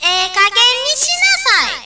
関西弁バージョン